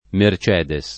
vai all'elenco alfabetico delle voci ingrandisci il carattere 100% rimpicciolisci il carattere stampa invia tramite posta elettronica codividi su Facebook Mercedes [ mer ©$ de S ; ted. mer Z% ede S ] pers. f. — anche marca d’automobili